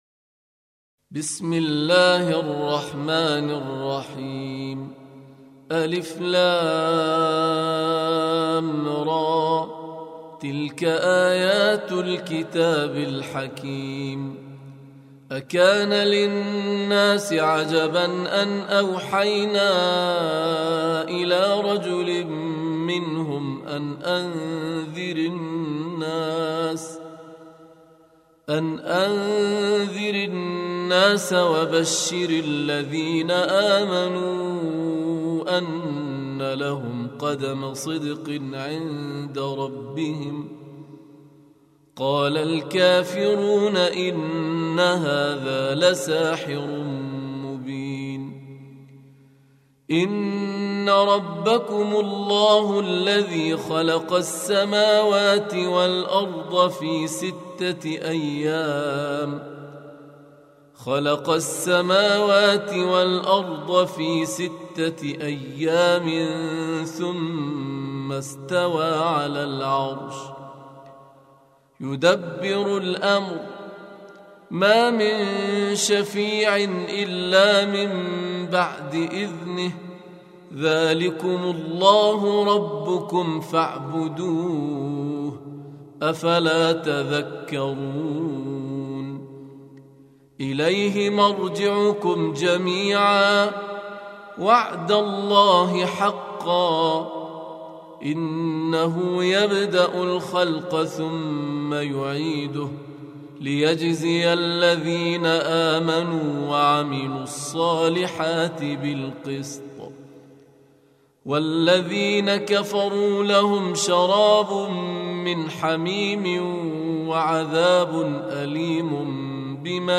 Surah Sequence تتابع السورة Download Surah حمّل السورة Reciting Murattalah Audio for 10. Surah Y�nus سورة يونس N.B *Surah Includes Al-Basmalah Reciters Sequents تتابع التلاوات Reciters Repeats تكرار التلاوات